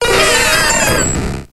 Grito de Mew.ogg
Grito_de_Mew.ogg